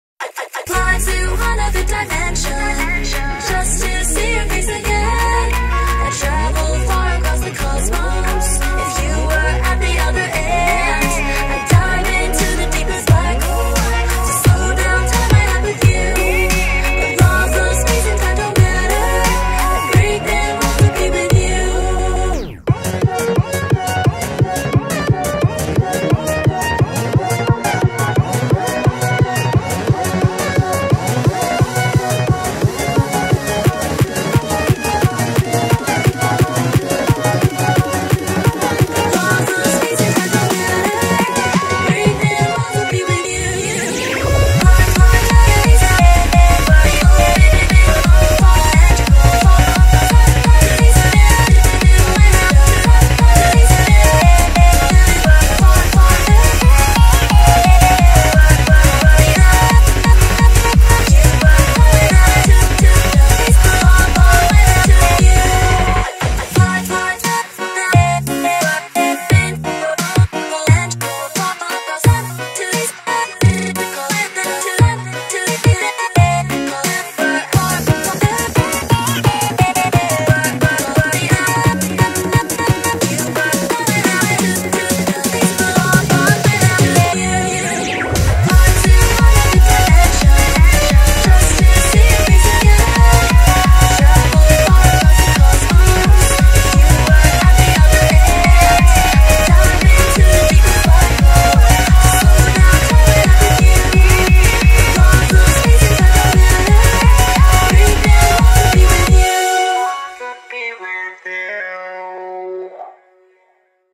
BPM88-175
Audio QualityPerfect (High Quality)
Comments[HARDCORE]